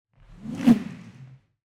Whoosh_Source_Rope_03.wav